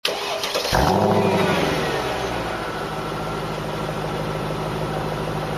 Index of /server/sound/vehicles/lwcars/merc_slk55
startup.wav